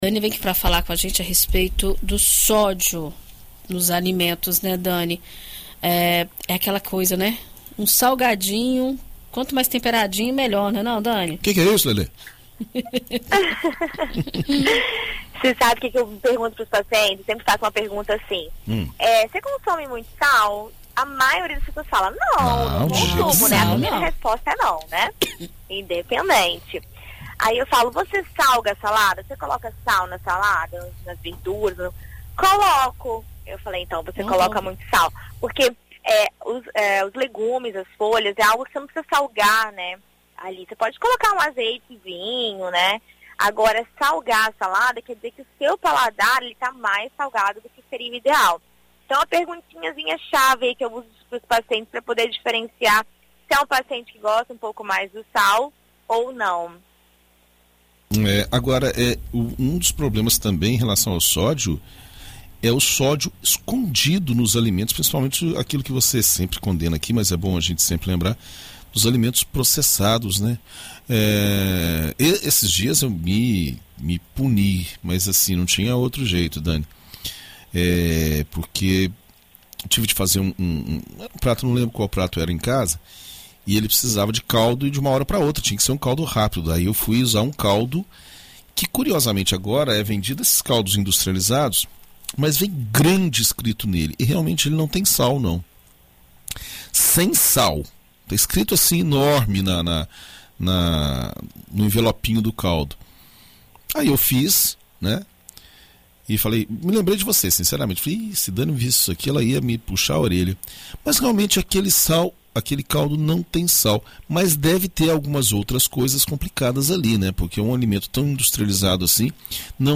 Na coluna Viver Bem desta quarta-feira (24), na BandNews FM Espírito Santo